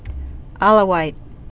lə-wīt) also A·la·wi lä-wē)